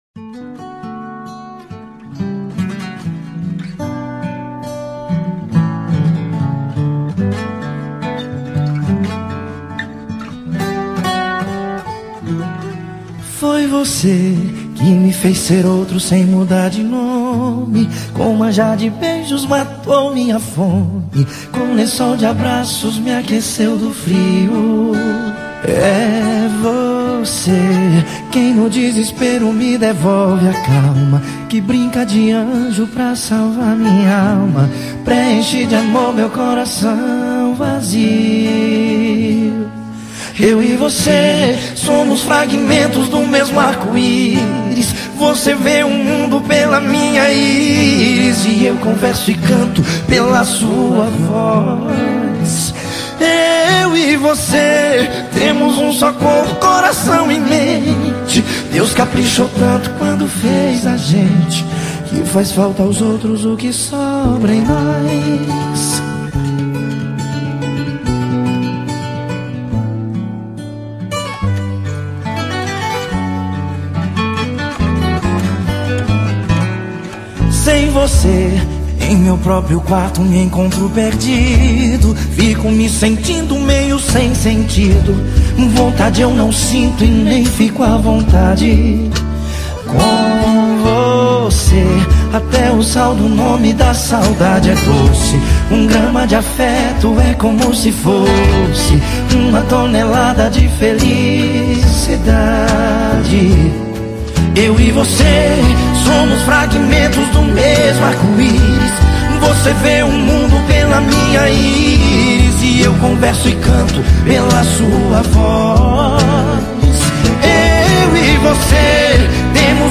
Sertanejas Para Ouvir: Clik na Musica.